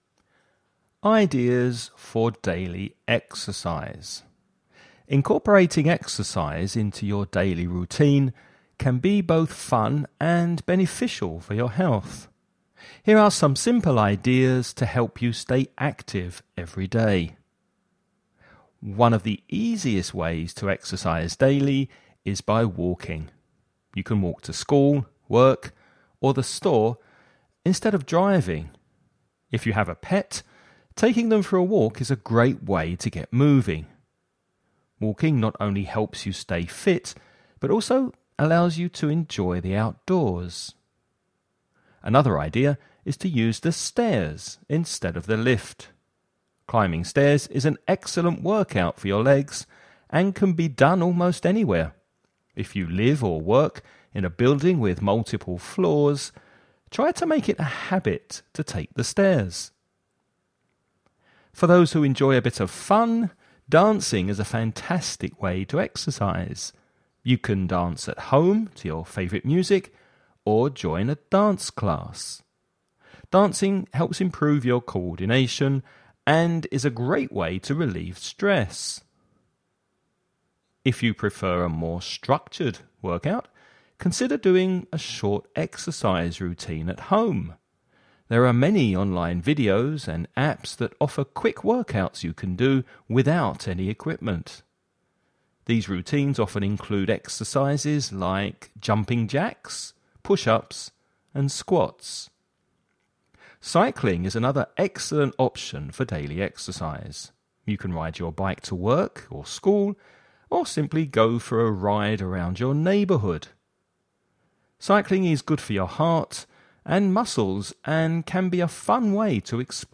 You’re going to listen to a man talking about taking daily exercise.